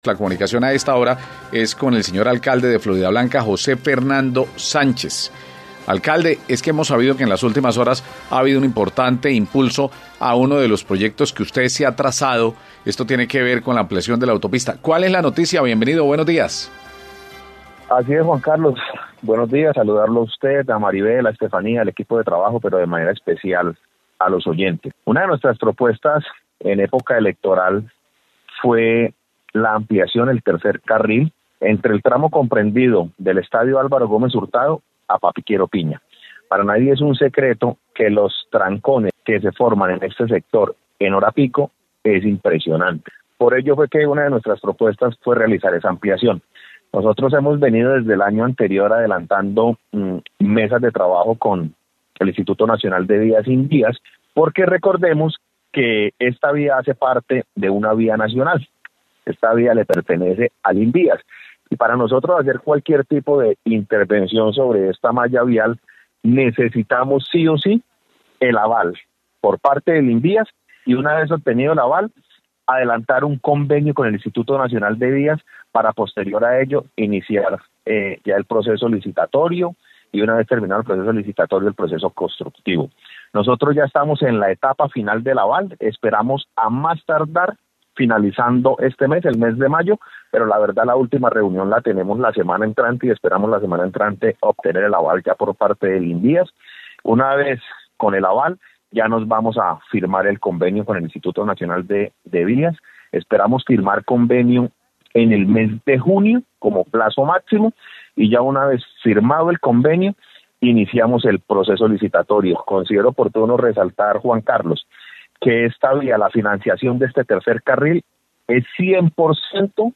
José Fernando Sánchez, Alcalde de Floridablanca